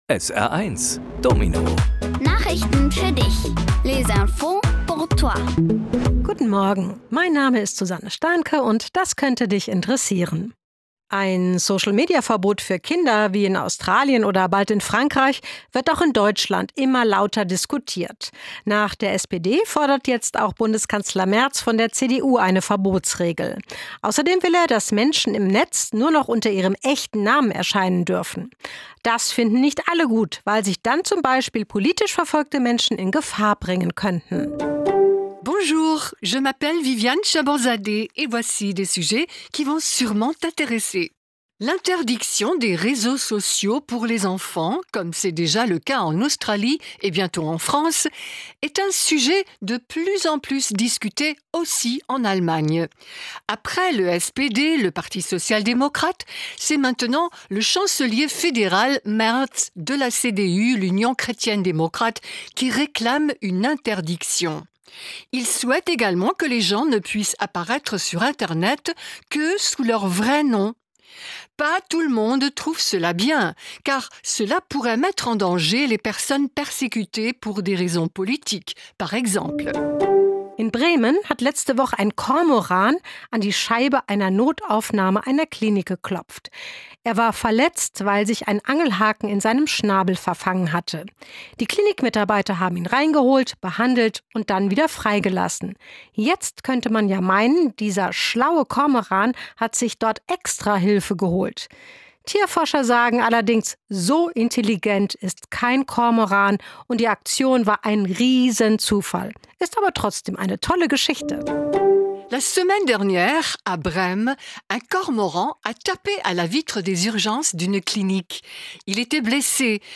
Kindgerechte Nachrichten auf Deutsch und Französisch: